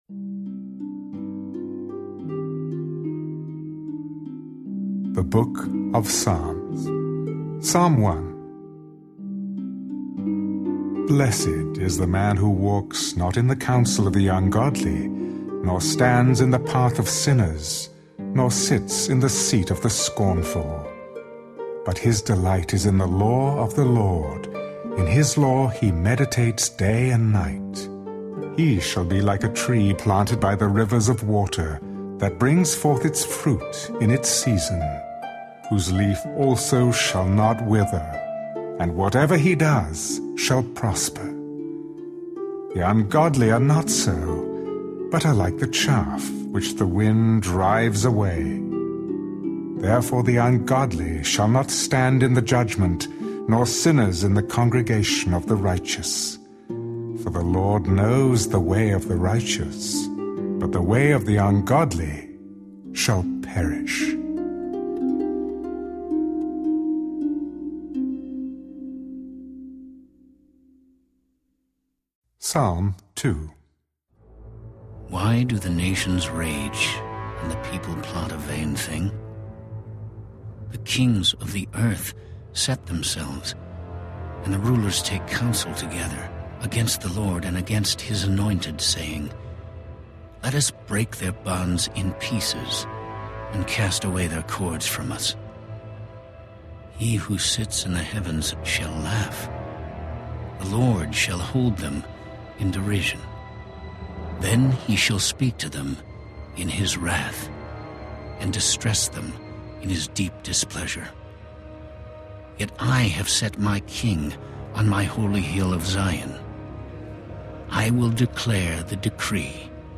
This faithful rendering of the New King James Version presents the Bible in more than 90 hours of compelling, dramatic audio theater format. This world-class audio production immerses listeners in the dramatic reality of the Scriptures as never before, with an original music score by composer Stefano Mainetti (Abba Pater), feature-film quality sound effects, and compelling narration by Michael York and the work of over 500 actors.
Each beloved book of the Bible comes to life with outstanding performances by Jim Caviezel as Jesus, Richard Dreyfuss as Moses, Gary Sinise as David, Jason Alexander as Joseph, Marisa Tomei as Mary Magdalene, Stacy Keach as Paul, Louis Gossett, Jr. as John, Jon Voight as Abraham, Marcia Gay Harden as Esther, Joan Allen as Deborah, Max von Sydow as Noah, and Malcolm McDowell as Solomon.